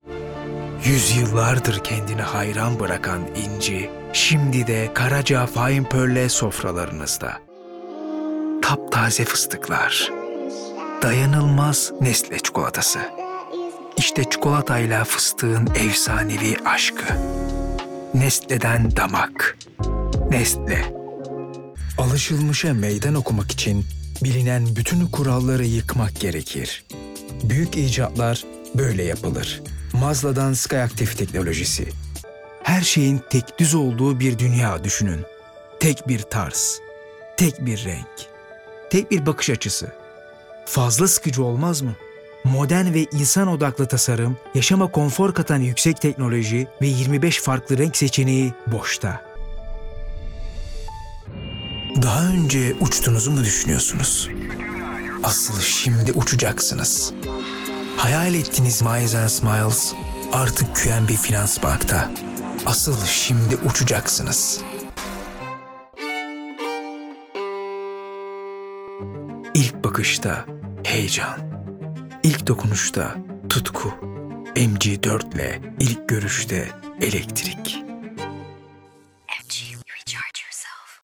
Neutral
Konversation
Warm